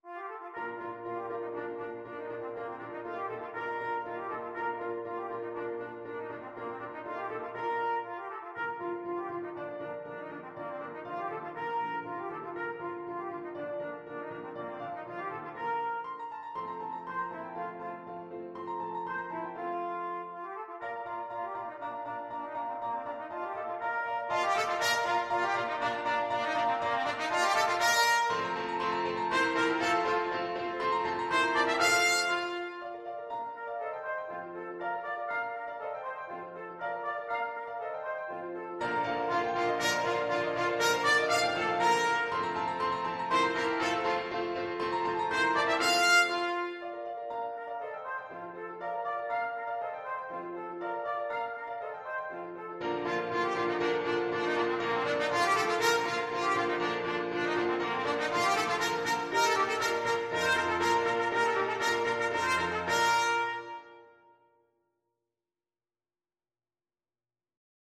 Classical Mozart, Wolfgang Amadeus Vivat Bacchus! Bacchus lebe! from The Abduction from the Seraglio Trumpet version
Trumpet
Bb major (Sounding Pitch) C major (Trumpet in Bb) (View more Bb major Music for Trumpet )
2/4 (View more 2/4 Music)
Bb4-F6
Classical (View more Classical Trumpet Music)